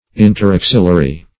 Search Result for " interaxillary" : The Collaborative International Dictionary of English v.0.48: Interaxillary \In`ter*ax"il*la*ry\, a. (Bot.) Situated within or between the axils of leaves.